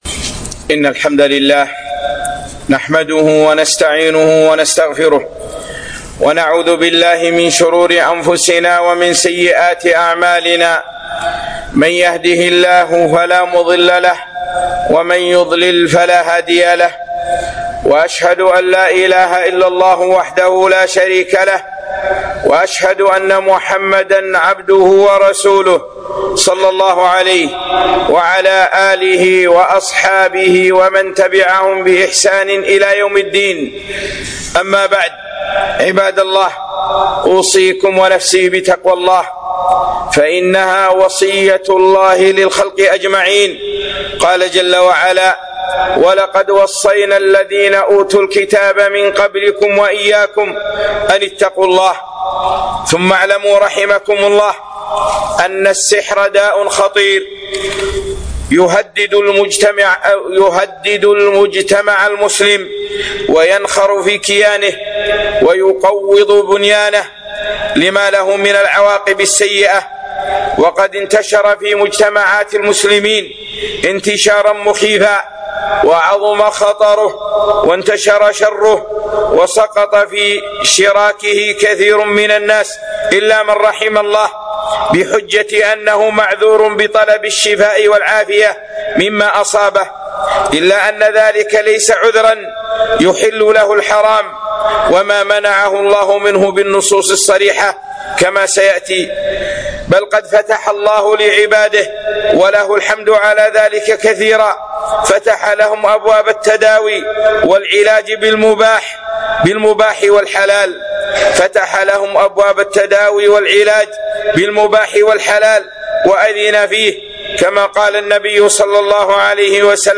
خطبة - الـسـحـر